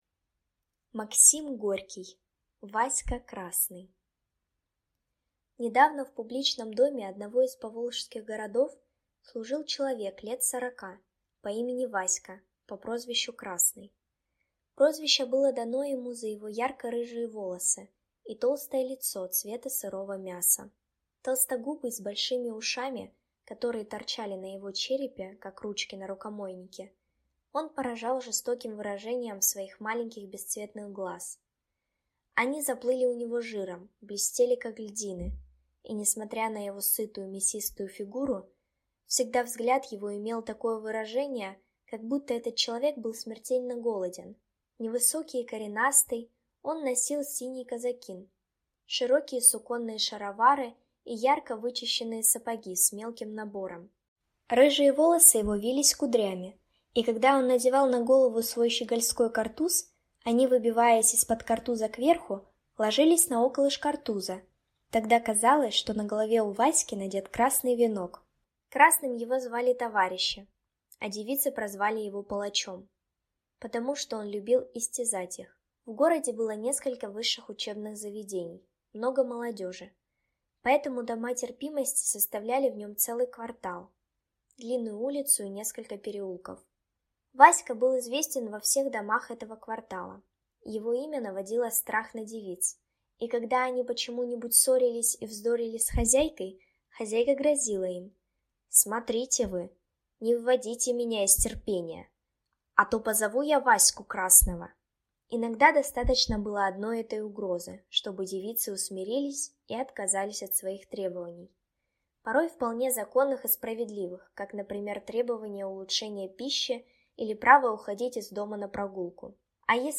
Аудиокнига Васька Красный | Библиотека аудиокниг